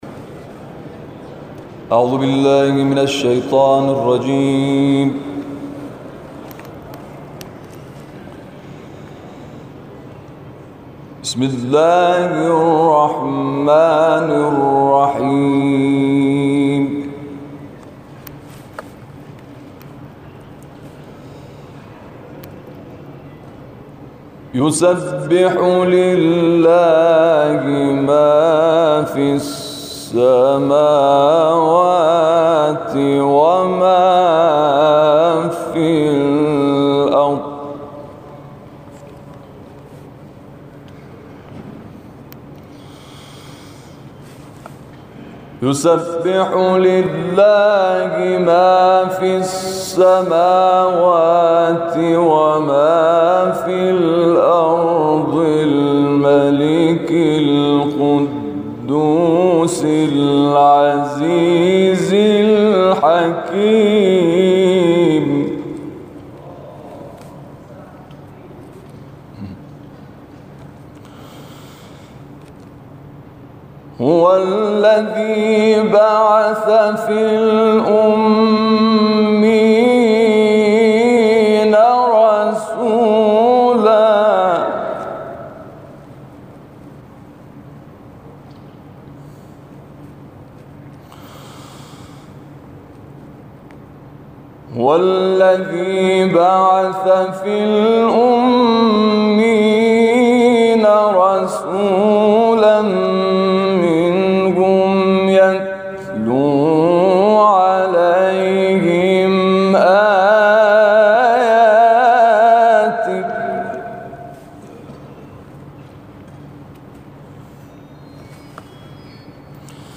قاری کشورمان روز گذشته با حضور در صحن علنی مجلس شورای اسلامی آیاتی از کلام‌الله مجید را تلاوت کرد.